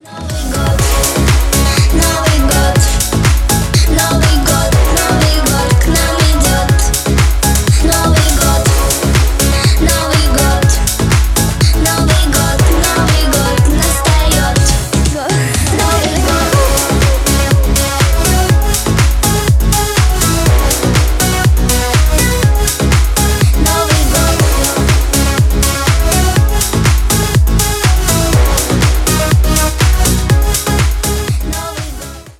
новогодние